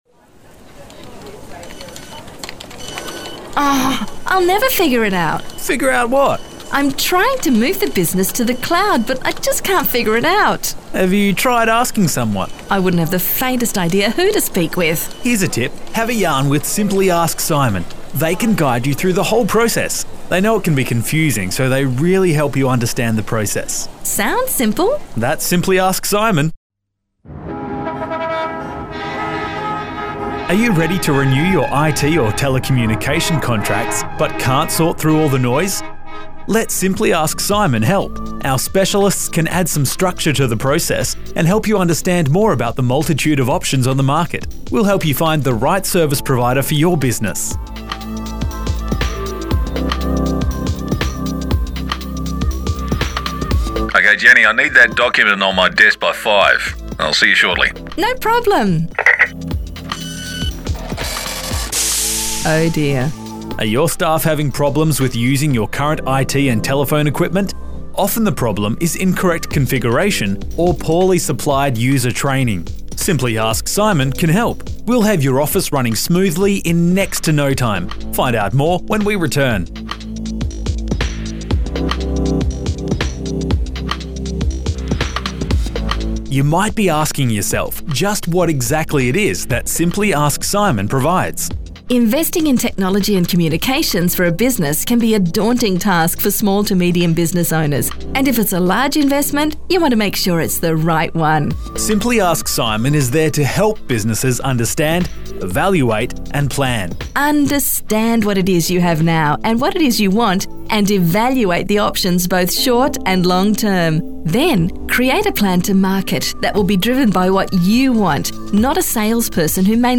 I've included our business cards and some of messages on hold we are running.